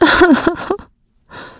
Valance-Vox_Sad1.wav